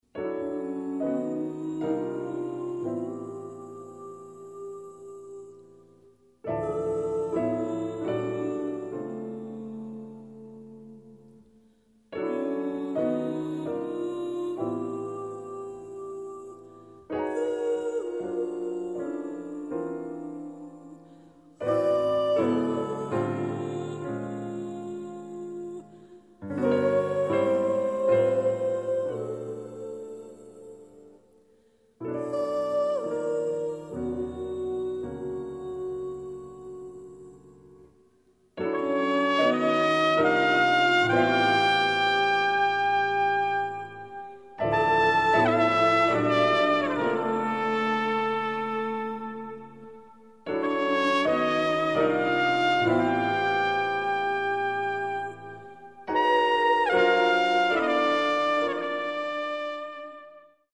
Piano/ Synthesiser
Trumpet/ Flugelhorn